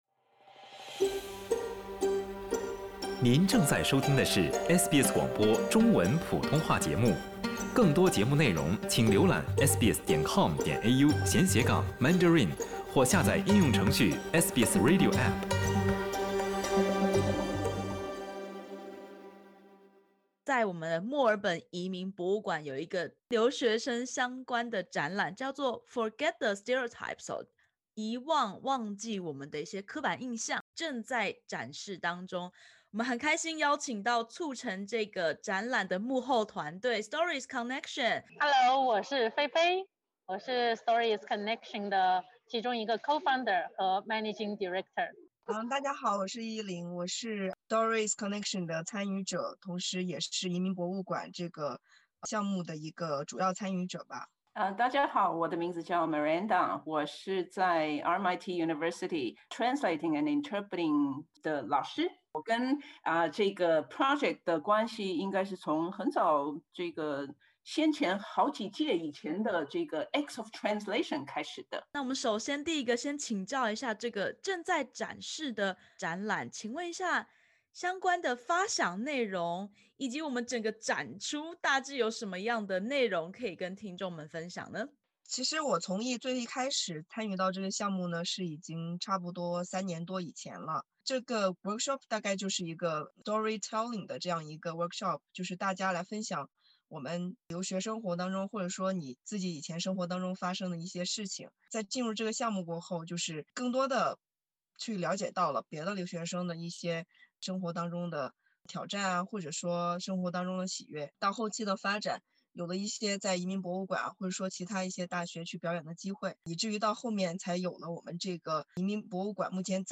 墨尔本移民博物馆特展《忘掉刻板印象》，呈现多元的留学生面貌，每个人都有独特故事。点击首图收听采访音频。